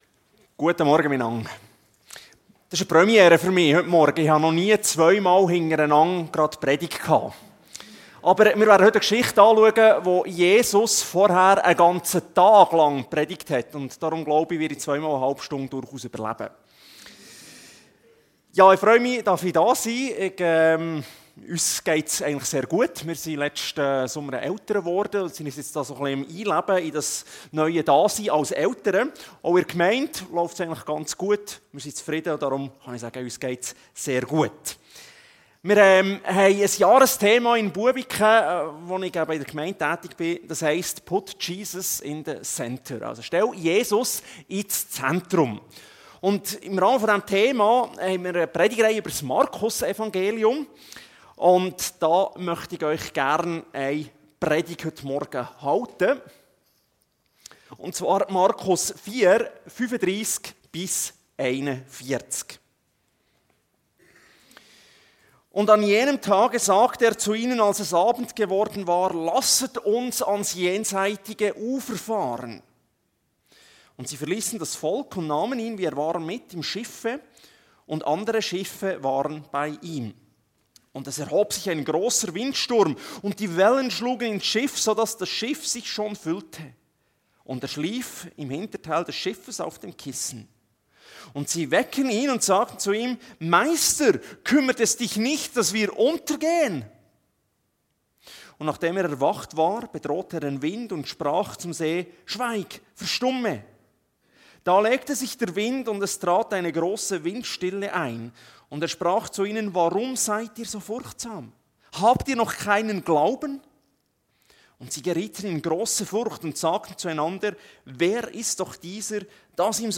Kategorie: Predigt Tags: 2.